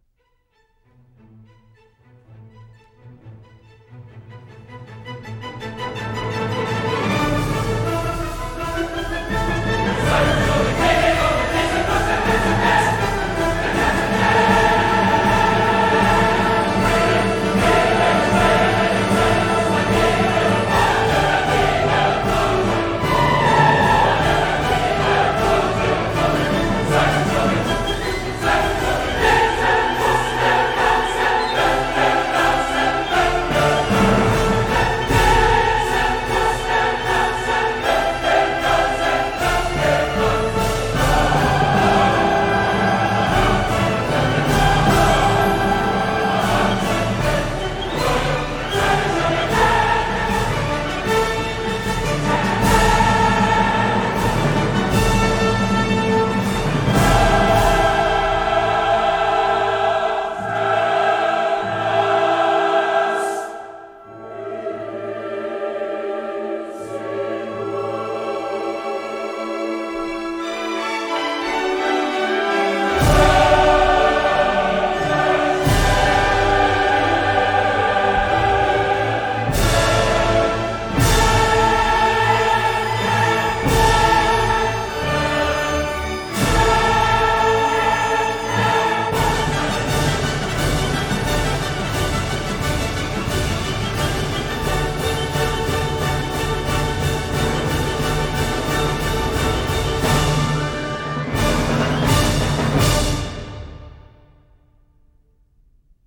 By the way, the snippets of audio we’ve been listening to are from that 1972 recording of the Chicago Symphony Orchestra and Chorus, Georg Solti conducting, recorded at the Krannert Center of Performing Arts on the campus of the University of Illinois.
Listen to the audio)   Here is a translation of the text of what the chorus sings: